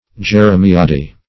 Meaning of jeremiade. jeremiade synonyms, pronunciation, spelling and more from Free Dictionary.
Search Result for " jeremiade" : The Collaborative International Dictionary of English v.0.48: Jeremiad \Jer`e*mi"ad\, Jeremiade \Jer`e*mi"ade\, n. [From Jeremiah, the prophet: cf. F. j['e]r['e]miade.]